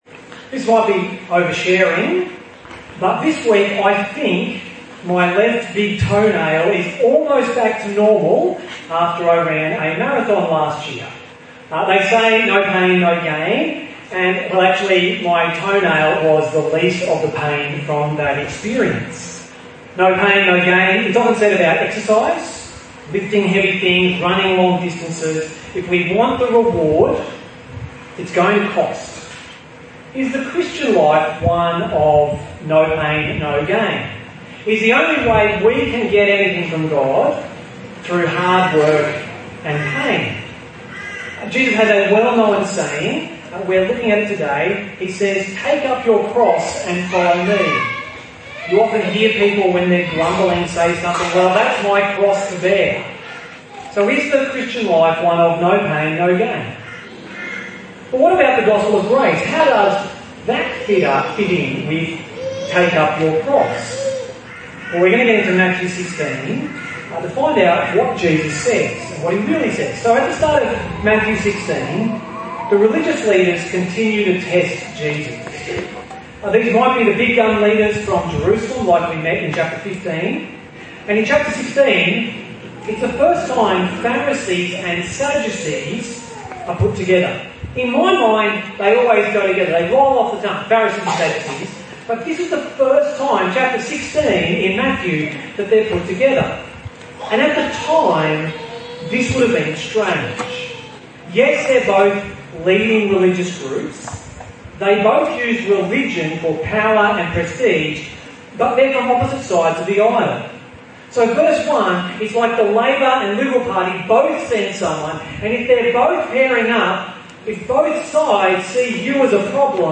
Weekly sermon podcast from Gympie Presbyterian Church